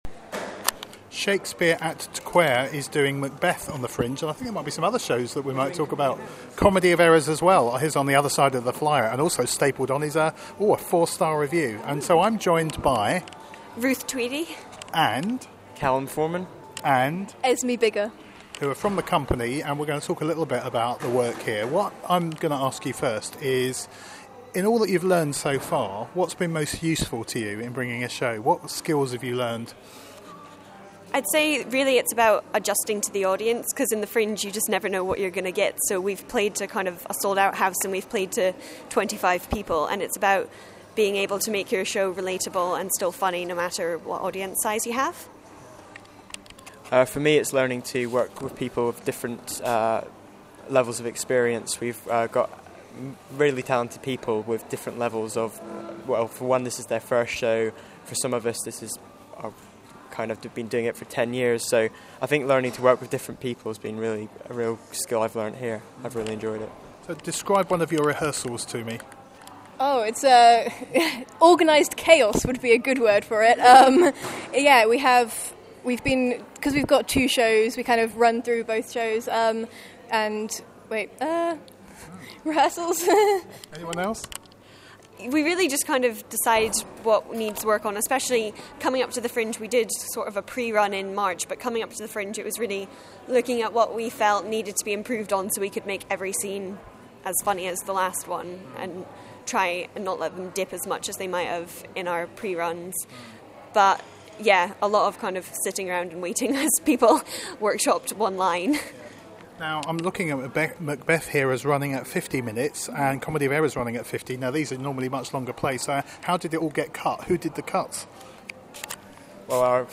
Listen to our interview with some of the cast from youth theatre company Traquair
listen-to-our-interview-with-some-of-the-cast-from-youth-theatre-company-traquair.mp3